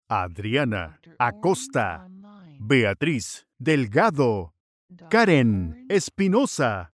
Incluye 3 PACKS de archivos de audio producidos con la más alta calidad:
9-DEMO-NOMBRES-APELLIDOS-FEMENINOS-GRADUACION-SOUNDMARK.mp3